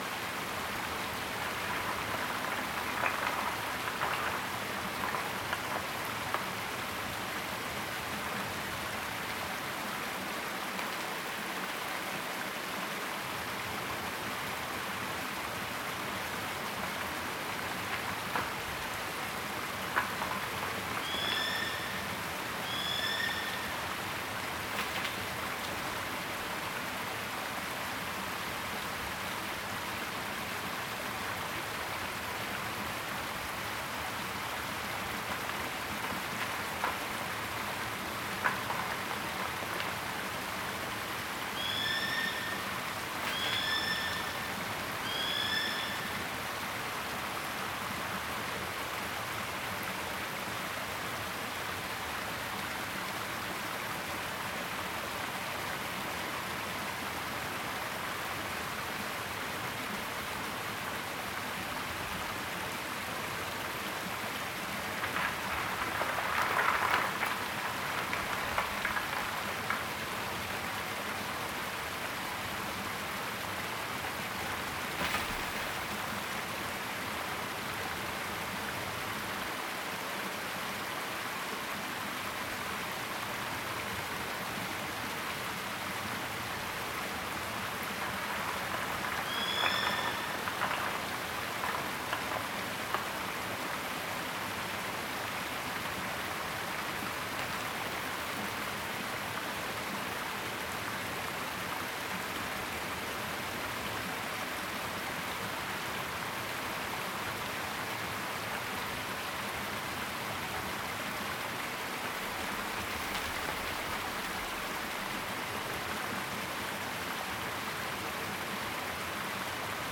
Mountain River.ogg